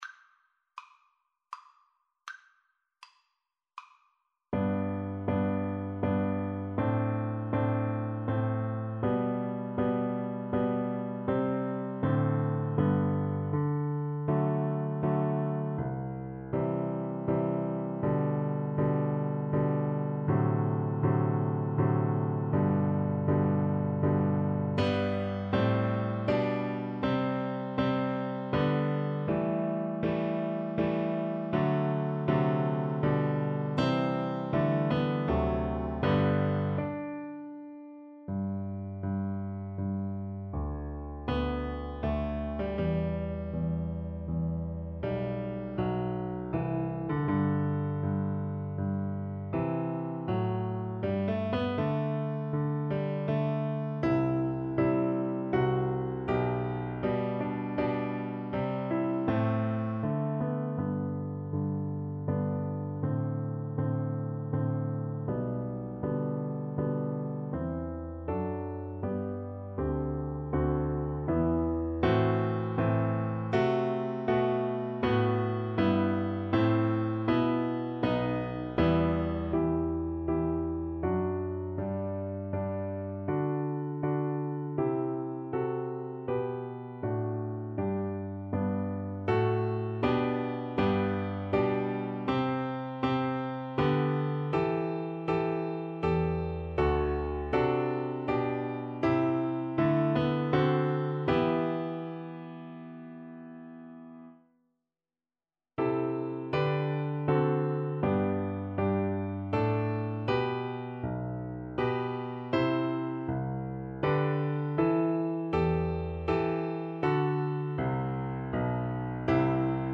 Free Sheet music for Piano Four Hands (Piano Duet)
Andante grandioso
3/4 (View more 3/4 Music)
Classical (View more Classical Piano Duet Music)